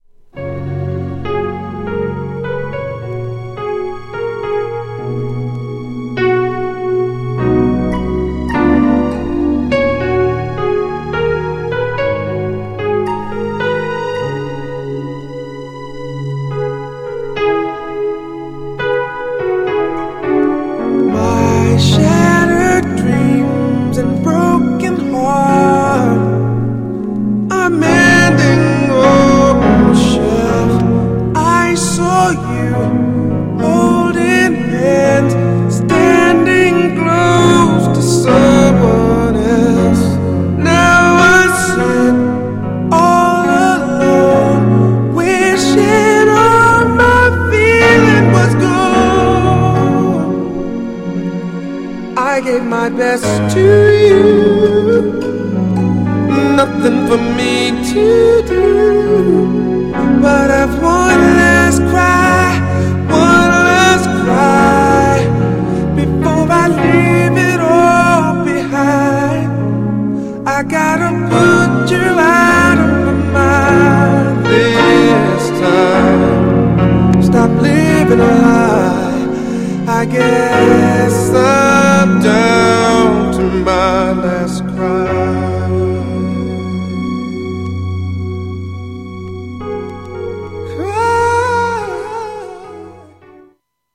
哀愁のギターが奏でる大人のメロウR＆B。
GENRE R&B
BPM 86〜90BPM